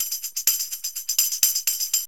TAMB LP 124.wav